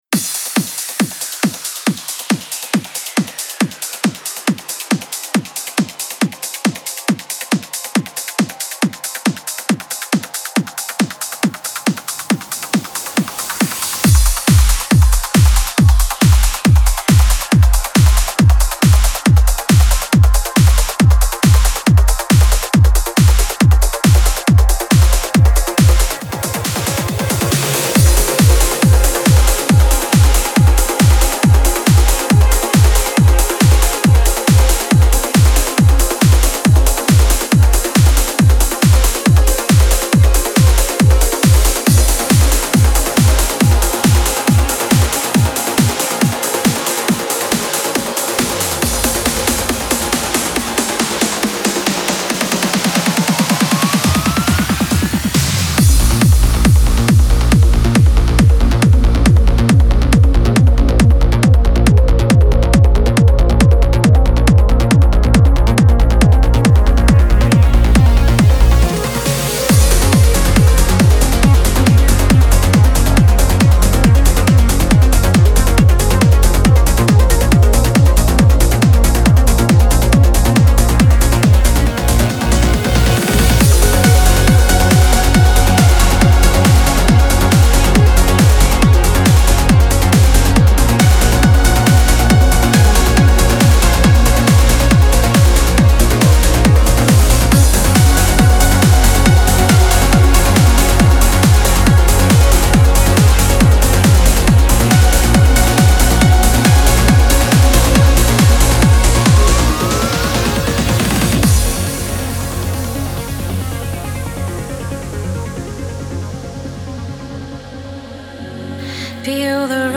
Genre: House, Trance, Electronic, Dance.